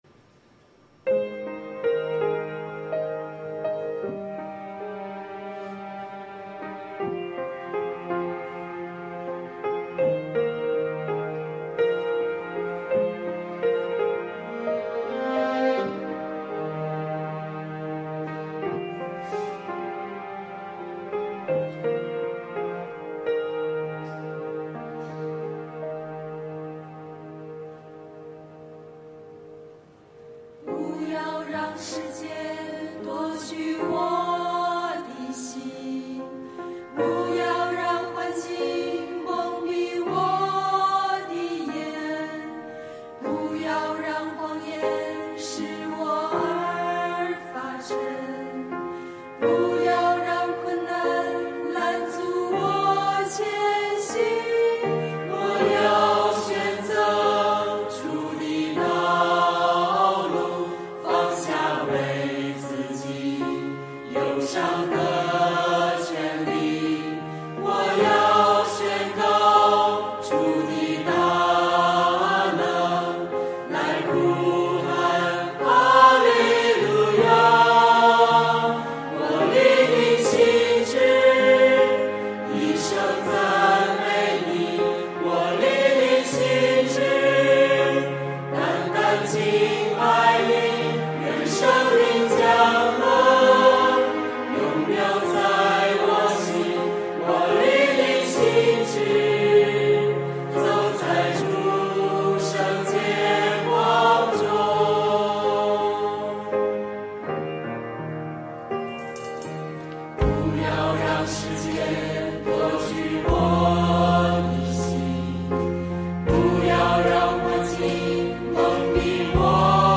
团契名称: 清泉诗班 新闻分类: 诗班献诗 音频: 下载证道音频 (如果无法下载请右键点击链接选择"另存为") 视频: 下载此视频 (如果无法下载请右键点击链接选择"另存为")